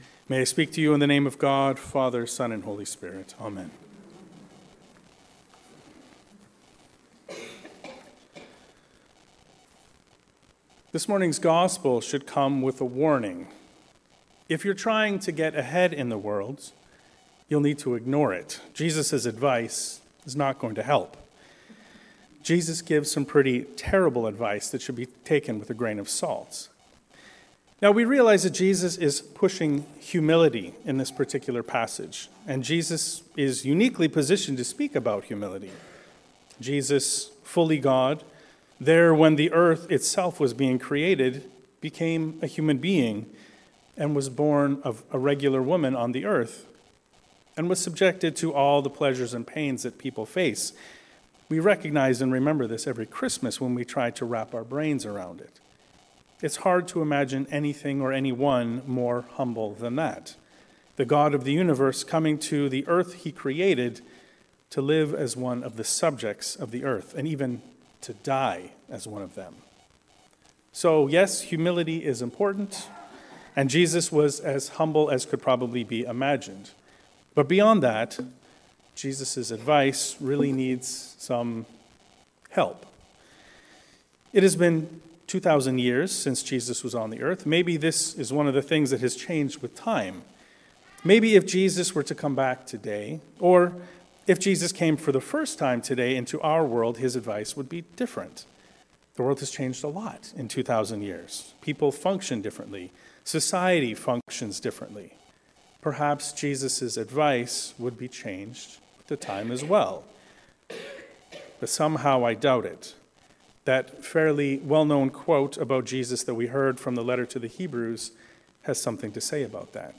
Jesus the Anti-Influencer. A sermon on Luke 14